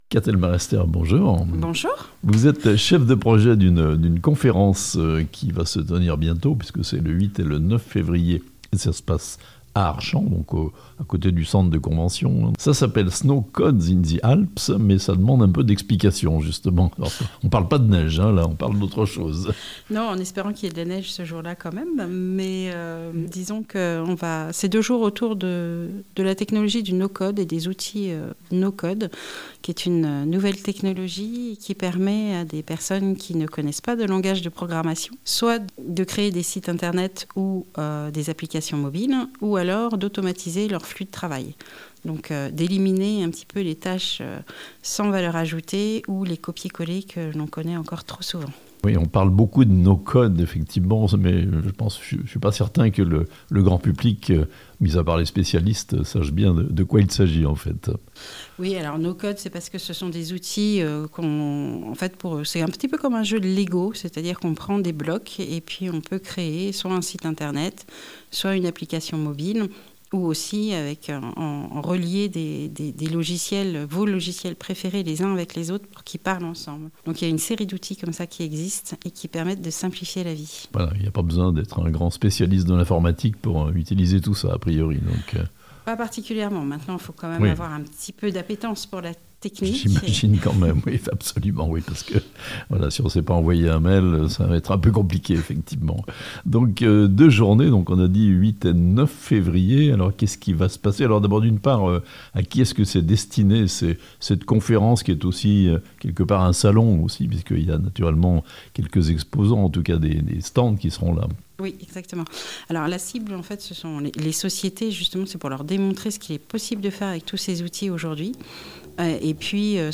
Archamps : un évènement sur deux journées consacré au "No Code" (interview)